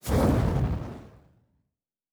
Weapon 14 Shoot 3 (Flamethrower).wav